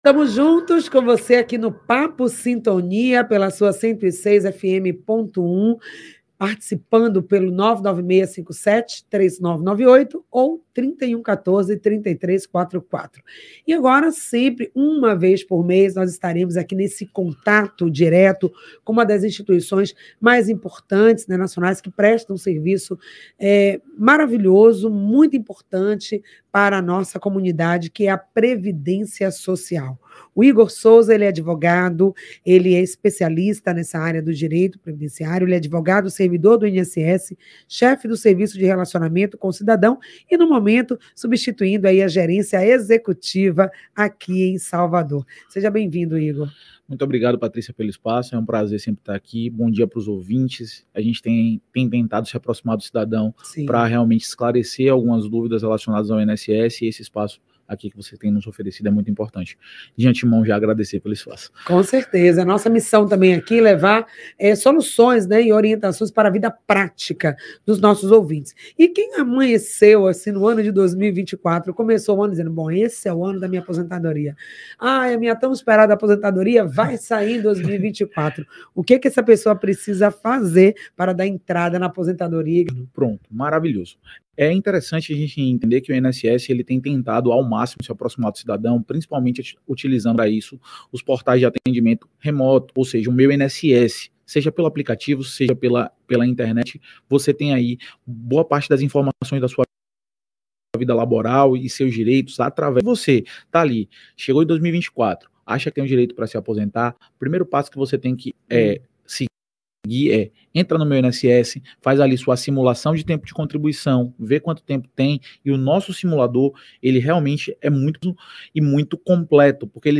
Áudio desta entrevista